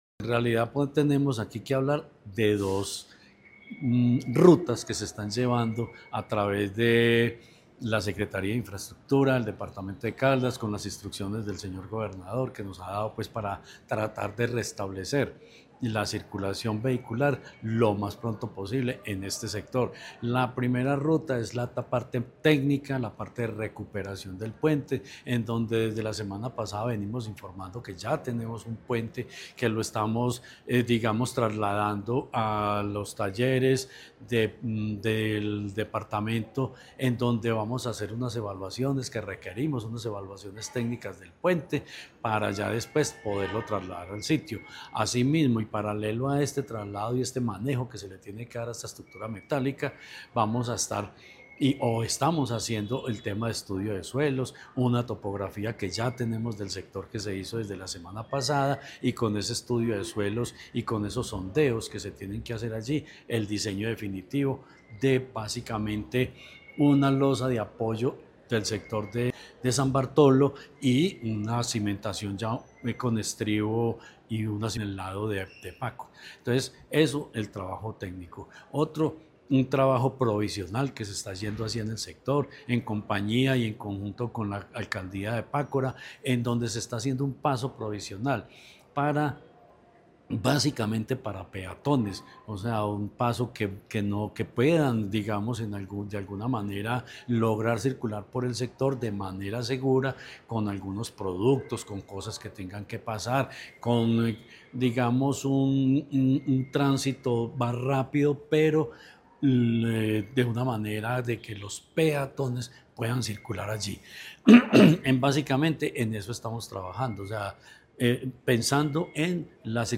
Secretario de Infraestructura, Jorge Ricardo Gutiérrez Cardona.